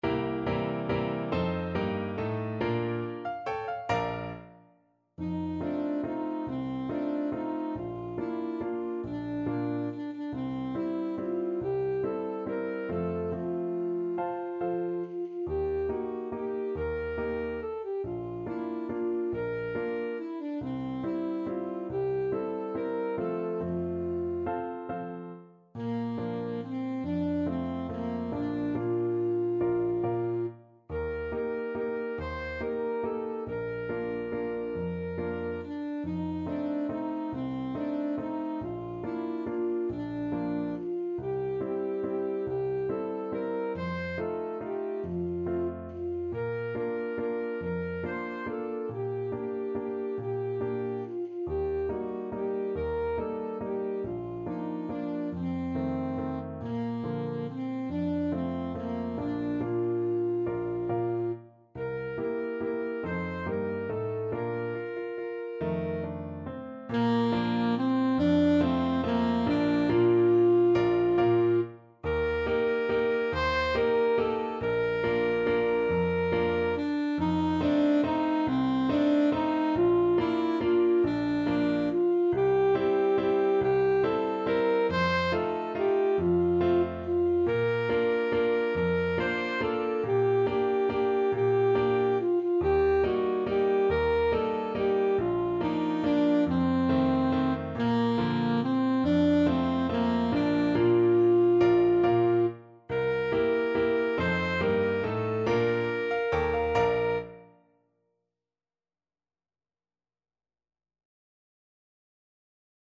Alto Saxophone version
3/4 (View more 3/4 Music)
Bb4-C6
=140 Moderato Valse